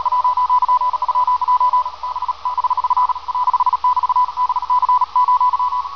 This is a BY (China) station sending fairly fast code. He is fairly weak but, no problem to copy except for the speed, ofcourse.
fast_faint_cw.wav